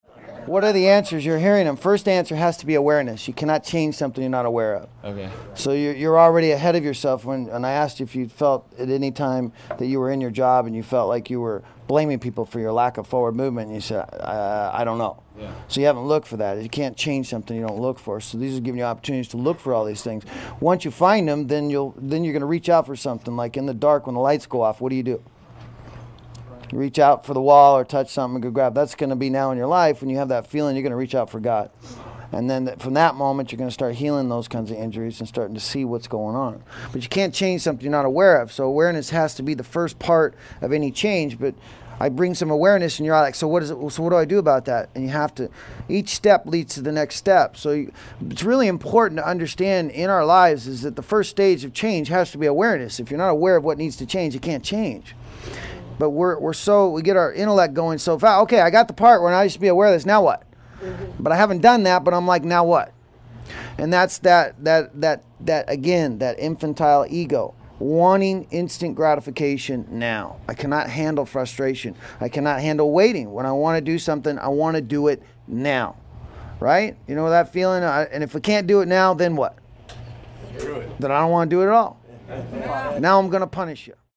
This audio archive is a compilation of many years of lecturing.
Within the lectures, you will hear people ask questions about why am I where I am, how can I get to a better place and what is blocking me. As you listen, you will realize that we all have similar problems because we live through our bodies instead of the light of our minds.